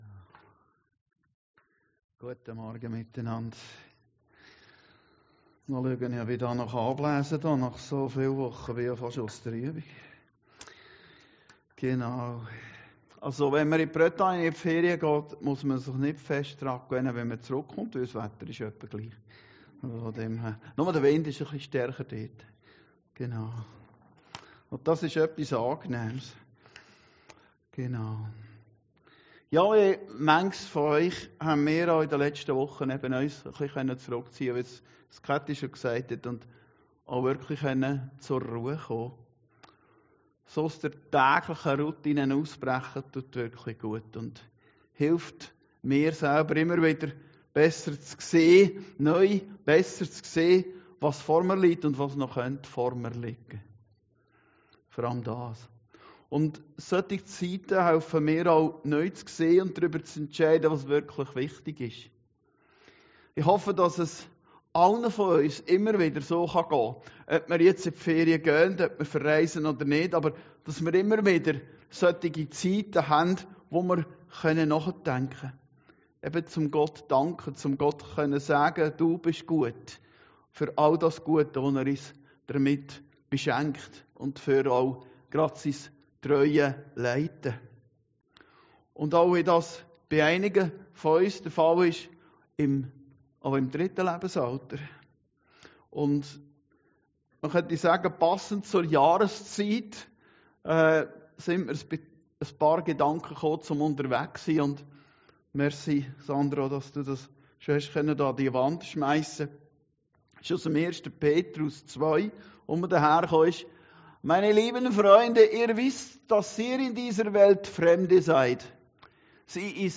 Predigten Heilsarmee Aargau Süd – DAS LEBEN IST EINE REISE UND KEIN ZIEL